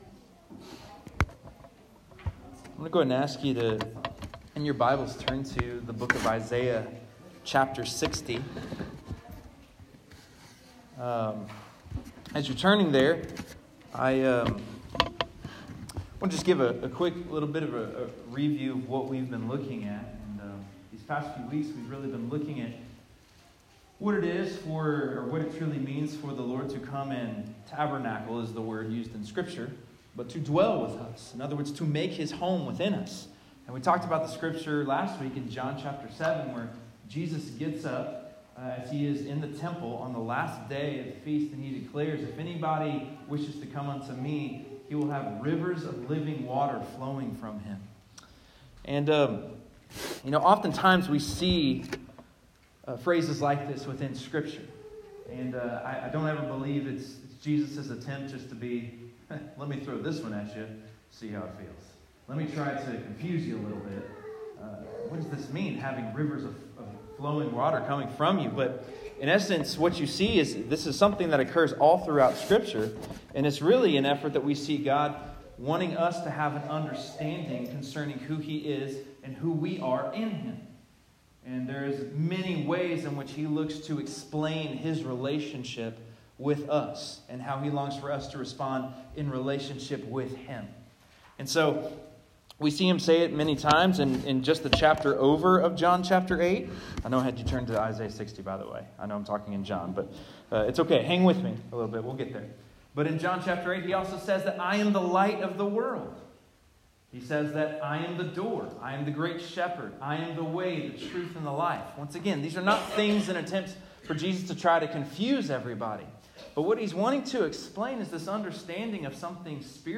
Sermons | FBC Sweet Springs